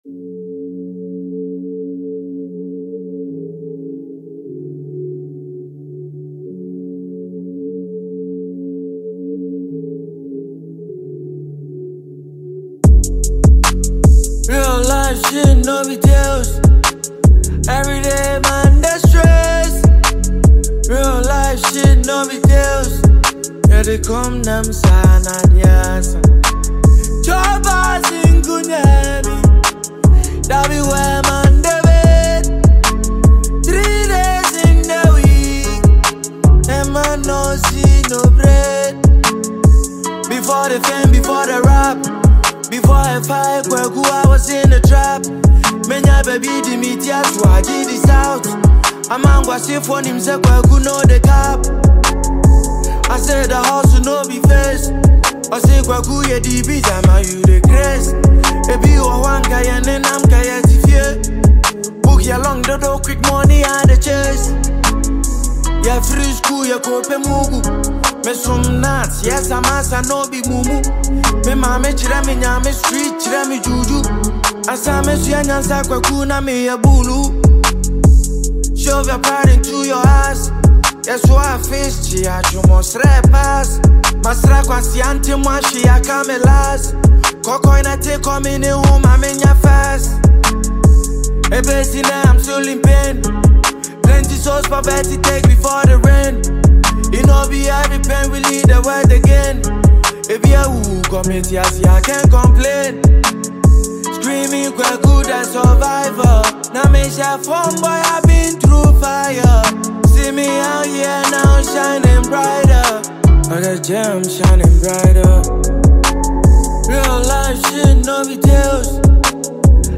featuring a laid-back beat
hip-hop music